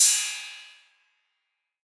808CY_2_Tape_ST.wav